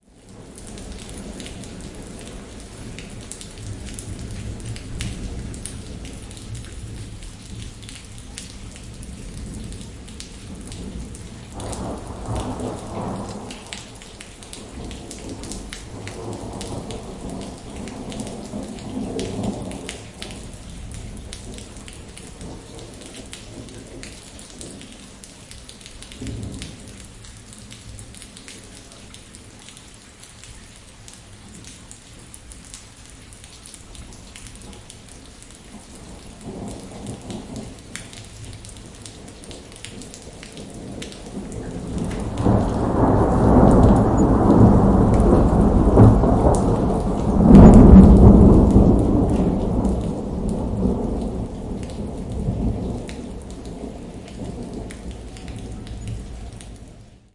雷雨天气 " THUNDERSTORM 1
大雷雨的声音。使用ZOOM H4N Pro和Rycote Mini Wind Screen录制声音。
Tag: 雷暴 暴雨 天气